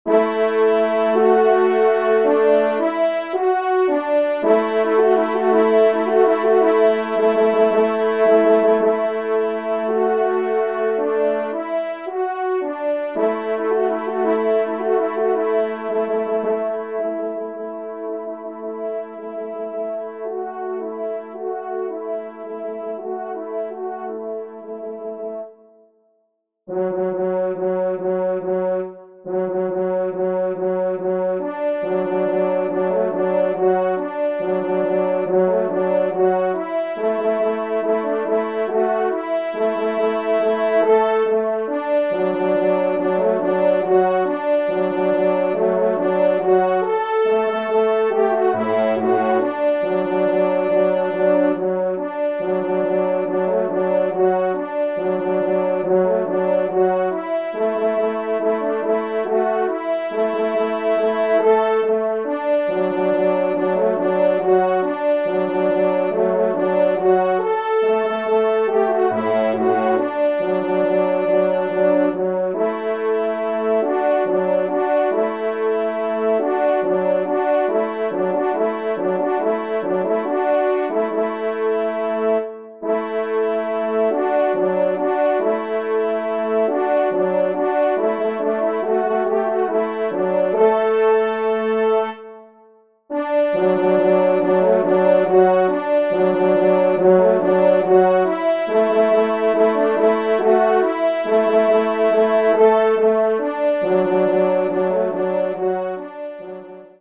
3e Trompe